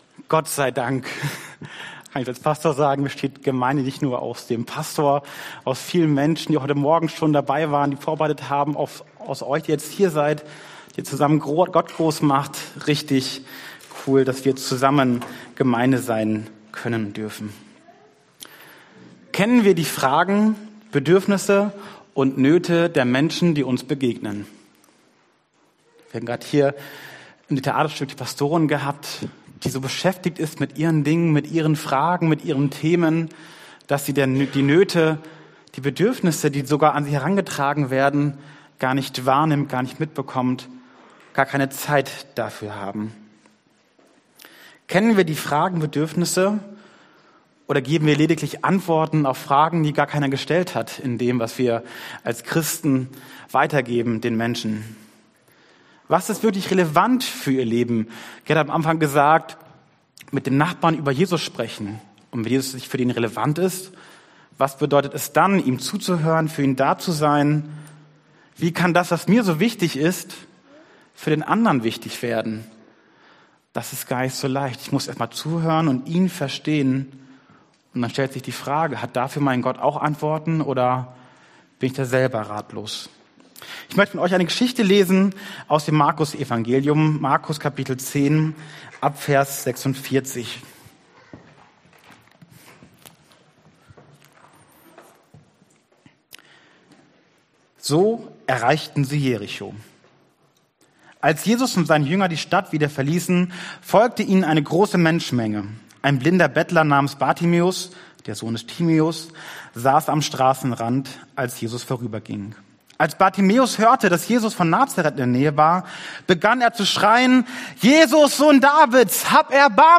Passage: Markus 10, 46ff Dienstart: Predigt